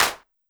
BAL Clap.wav